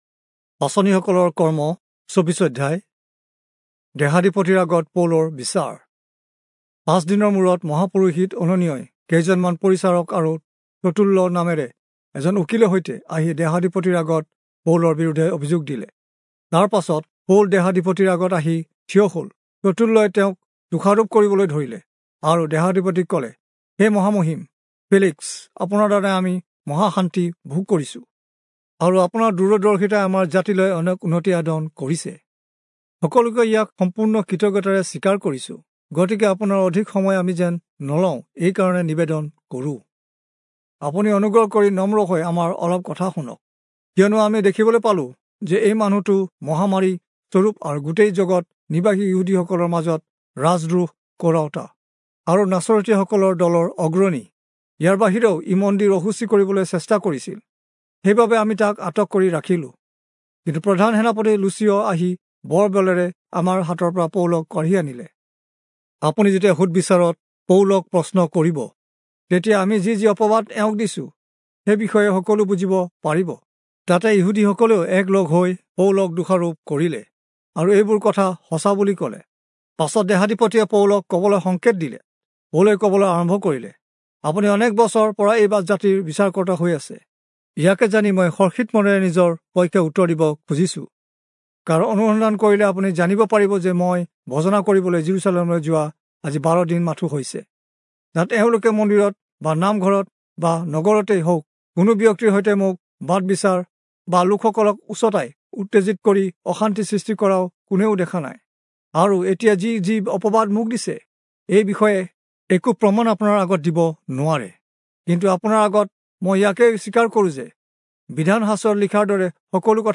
Assamese Audio Bible - Acts 8 in Orv bible version